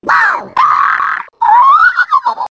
One of Wiggler's voice clips in Mario Kart 7